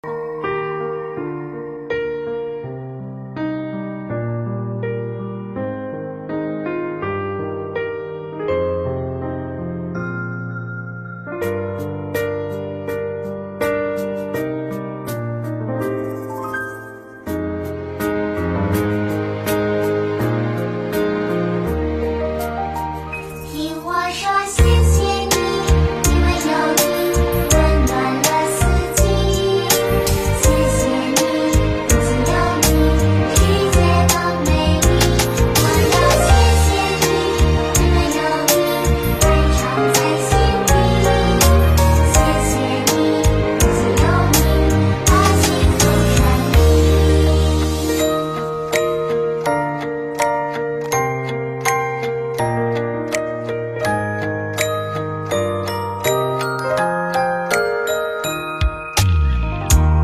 音频：温哥华观音堂2023年元宵节素食聚餐花絮分享！